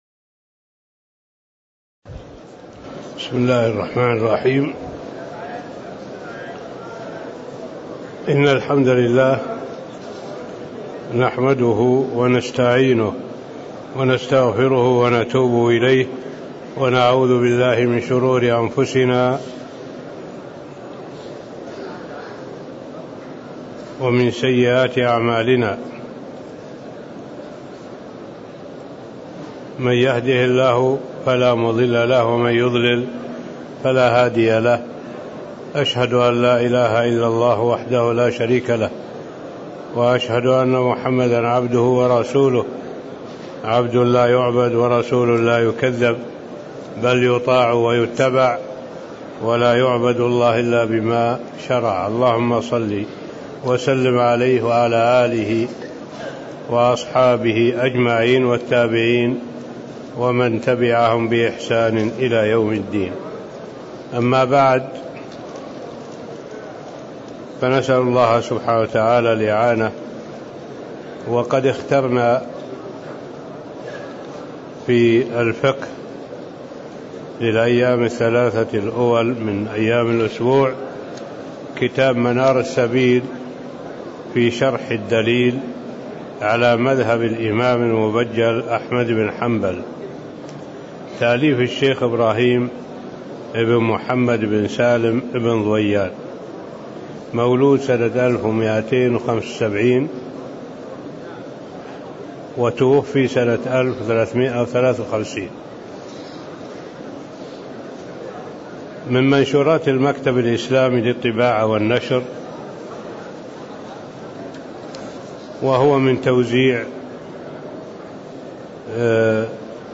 تاريخ النشر ١٦ جمادى الأولى ١٤٣٦ هـ المكان: المسجد النبوي الشيخ